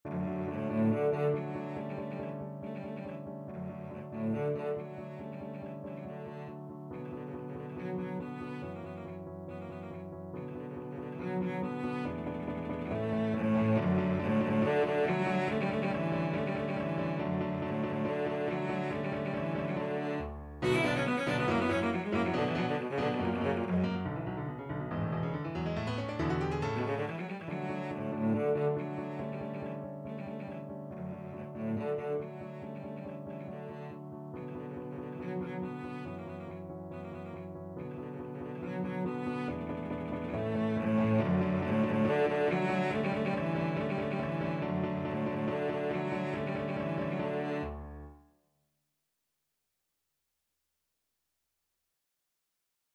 Classical Beethoven, Ludwig van Rondo a capriccio, Op.129 (Rage over a lost penny) (Main Theme) Cello version
~ = 140 Allegro vivace (View more music marked Allegro)
D major (Sounding Pitch) (View more D major Music for Cello )
Classical (View more Classical Cello Music)